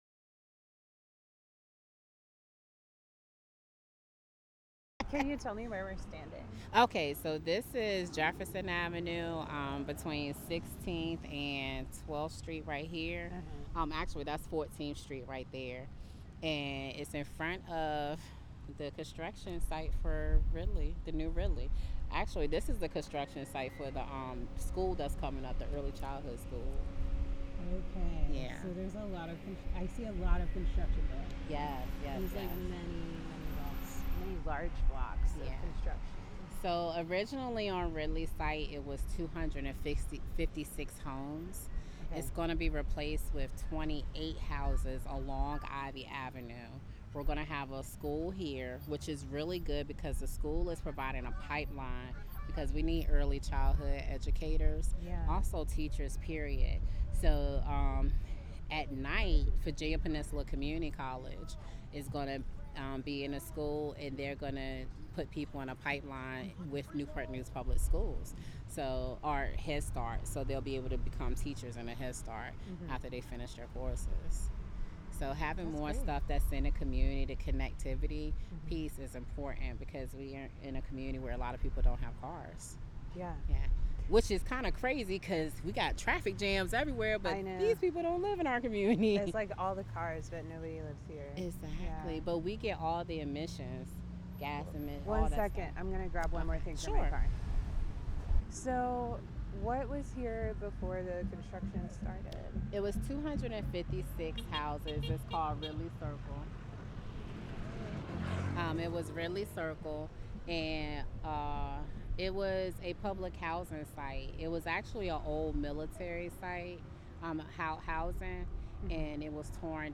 In this recording, the interviewee describes her experience working with the CNI, her experiences with coal dust, and her hopes for solutions. She engages several passerby during the outdoors walking interview.
Note: There is audio disruption around minute 12:30-17:00.